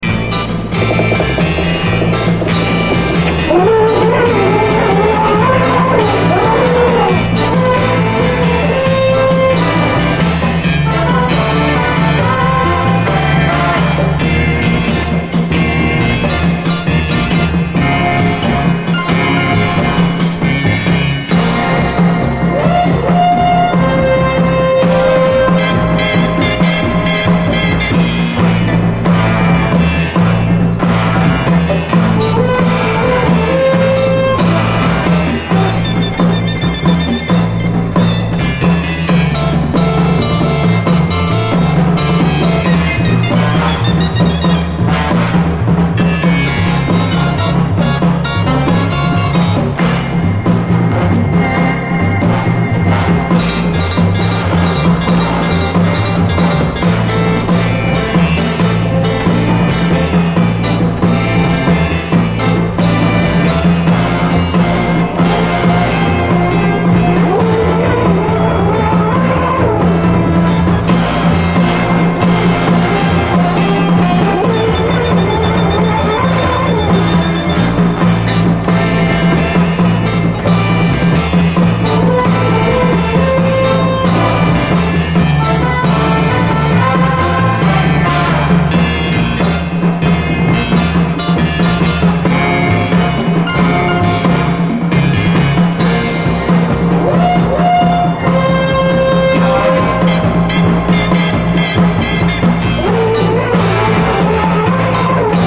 JAZZ FUNK / SOUL JAZZ, JAZZ
ハープシコードの鈍く妖しい音色で魅せるサイケデリック・ジャズ・ファンク
ドコドコと鳴るアフロなビートも妖しくてファンキー！
ホーンで巧みにゾウの鳴き声も表現してます。